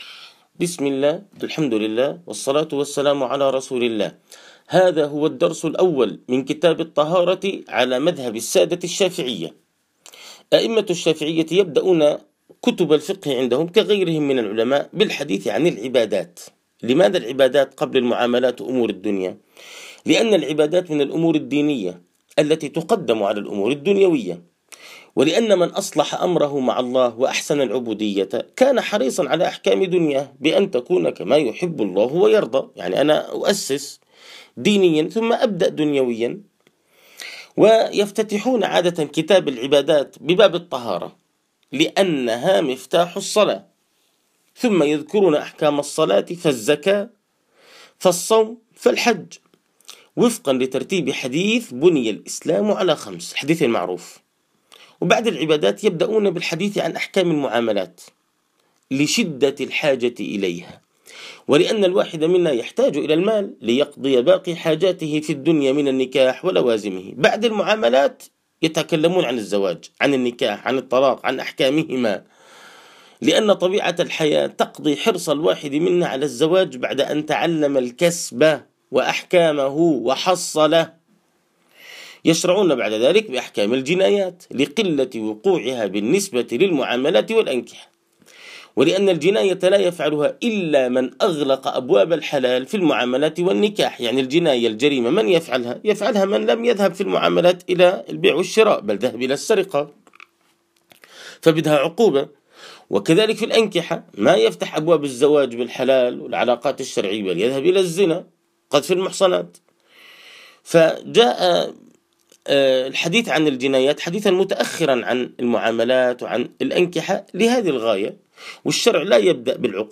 فقه الطهارة والصلاة 4: 1.الدرس الأول للفقه على المذهب الشافعي